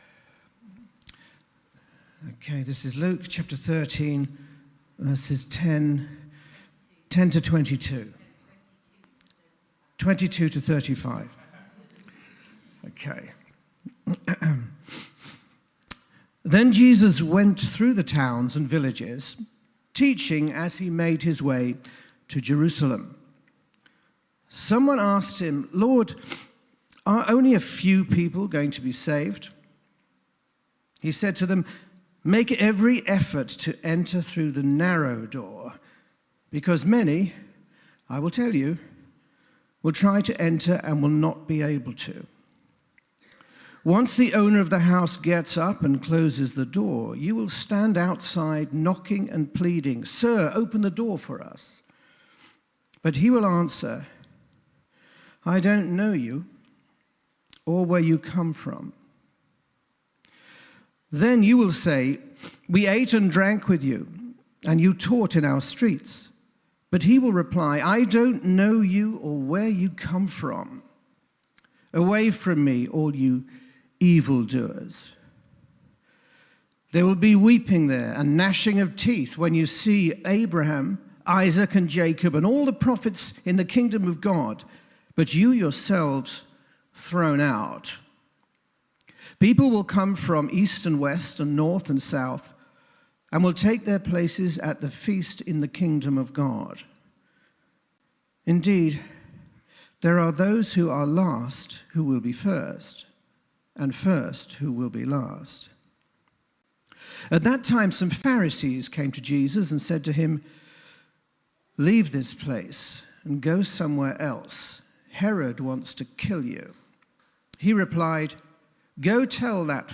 Here is our latest sermon podcast